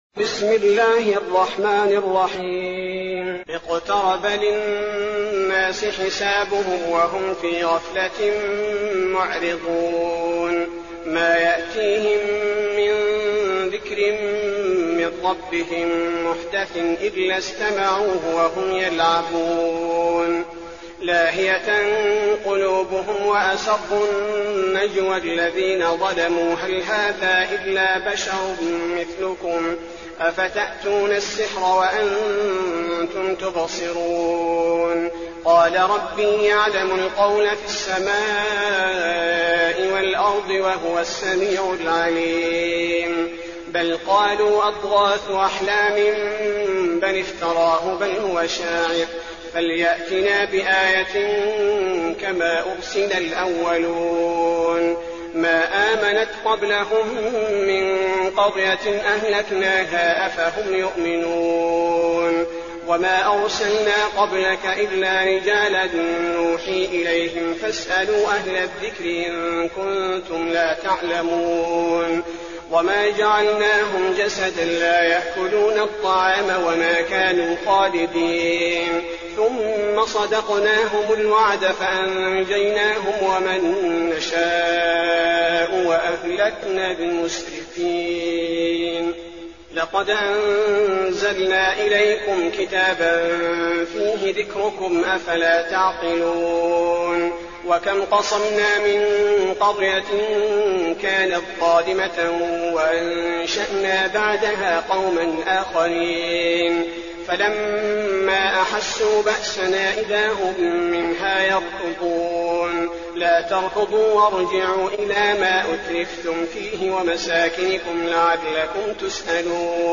تراويح الليلة السادسة عشر رمضان 1422هـ سورة الأنبياء كاملة Taraweeh 16 st night Ramadan 1422H from Surah Al-Anbiyaa > تراويح الحرم النبوي عام 1422 🕌 > التراويح - تلاوات الحرمين